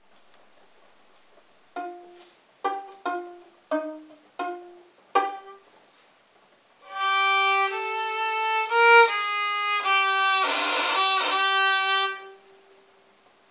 DSC_0022-Violon.mp3